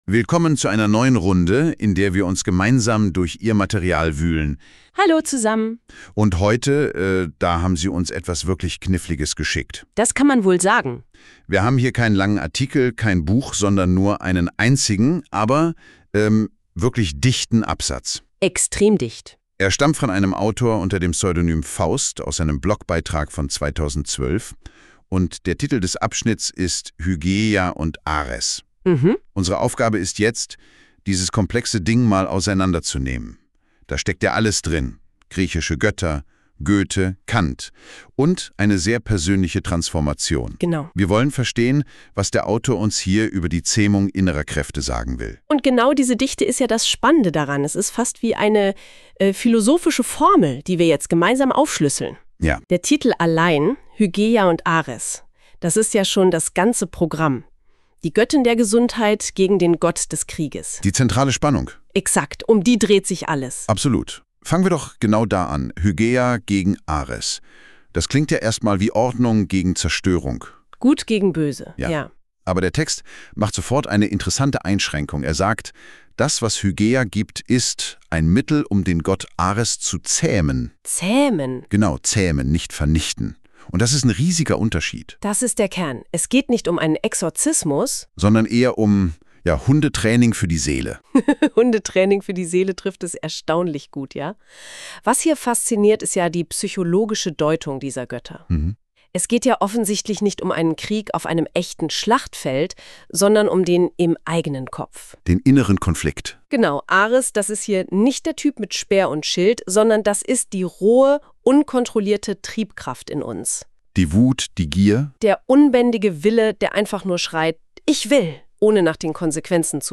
Podcast des poetologischen Fragmentes im Stil einer Rezension Die Zähmung der inneren Hyäne